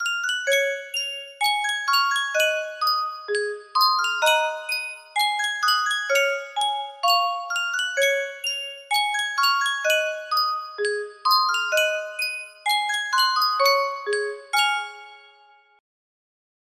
Yunsheng Music Box - Old Spinning Wheel 6129 music box melody
Full range 60